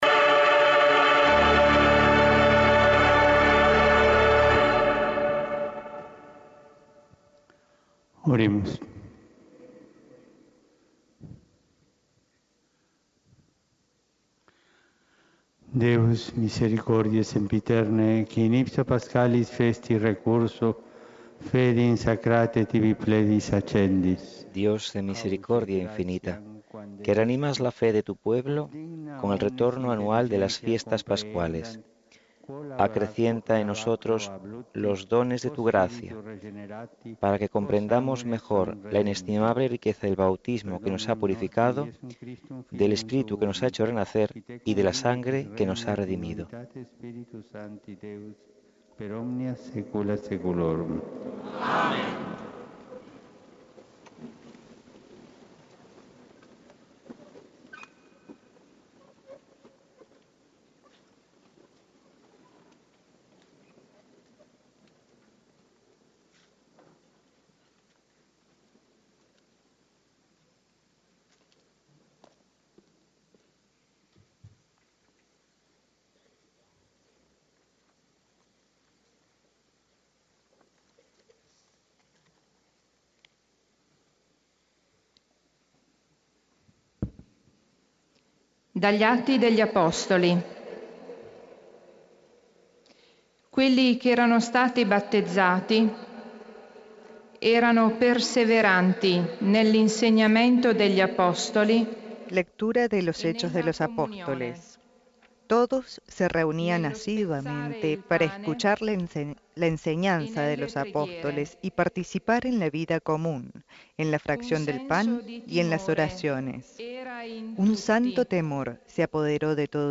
Radio Martí retransmite la Solemne Santa Misa oficiada por el Papa Francisco junto al Papa emérito Benedicto XV